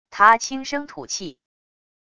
他轻声吐气wav音频